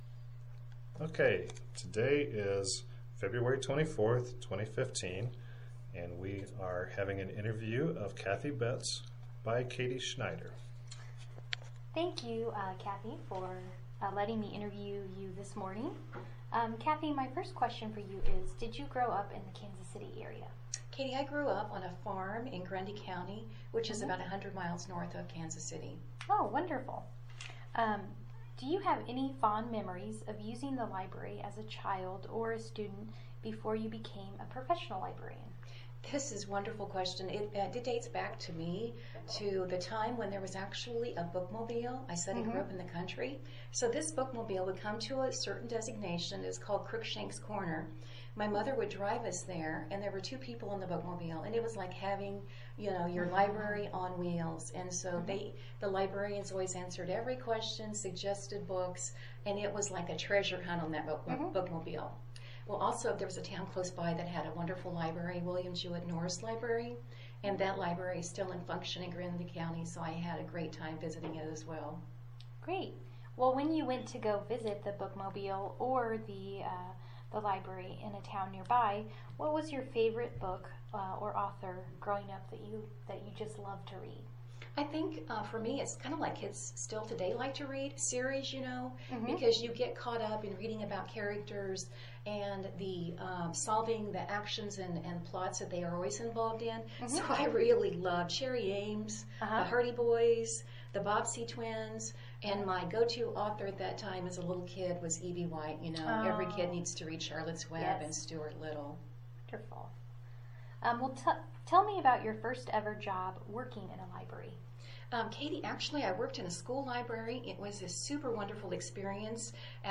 Interviewer Relation: Co-worker